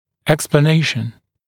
[ˌeksplə’neɪʃn][ˌэксплэ’нэйшн]объяснение, разъяснение